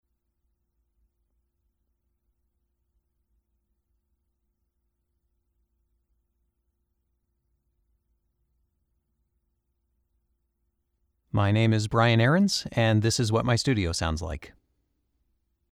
Male
American English (Native) , Canadian English (Native) , French Canadian
Approachable, Assured, Authoritative, Confident, Conversational, Corporate, Deep, Energetic, Engaging, Friendly, Funny, Gravitas, Natural, Posh, Reassuring, Sarcastic, Smooth, Soft, Upbeat, Versatile, Warm, Witty
commercial.mp3
Microphone: Sennheiser 416